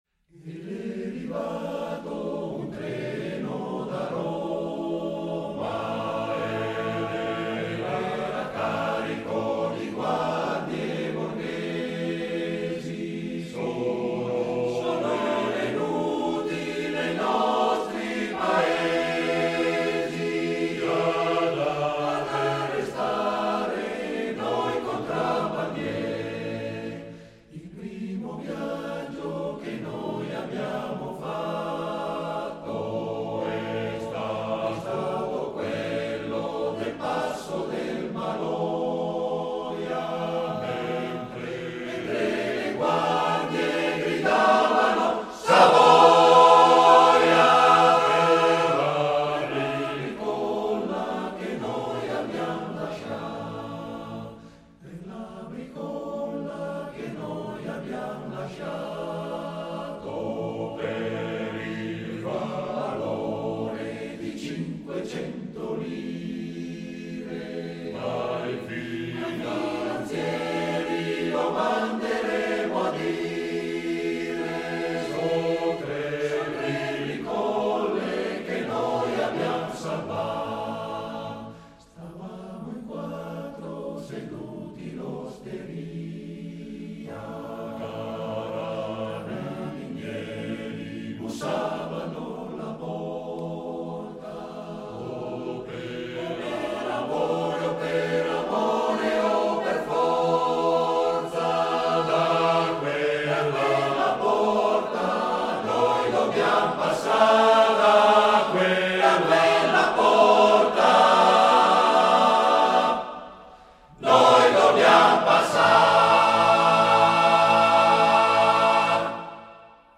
Esecutore: Coro Edelweiss